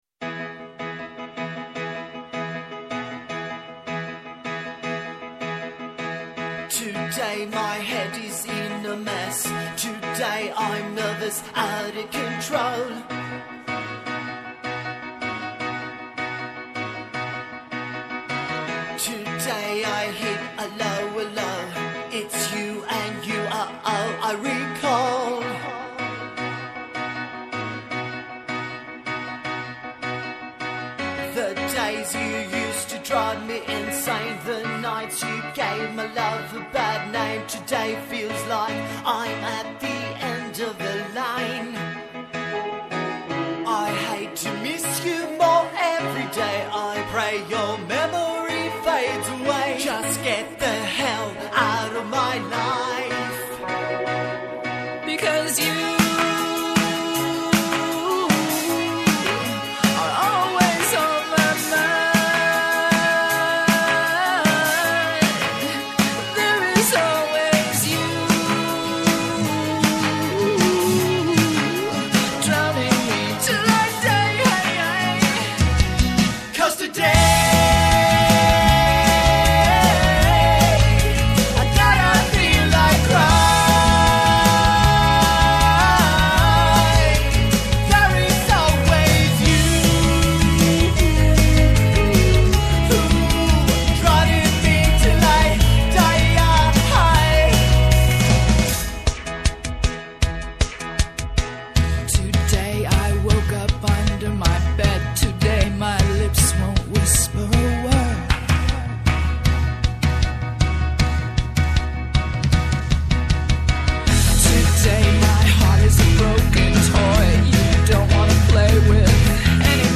-και η Δόμνα Μιχαηλίδου, Υφυπουργός Εργασίας